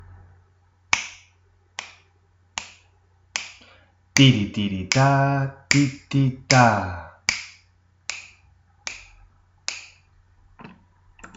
Primeiro, escoitamos como sería o ritmo chamando cada figura polo seu alcume, como xa temos visto antes. Como podedes comprobar, neste senso coinciden a perfección os golpes de voz coas sílabas rítmicas!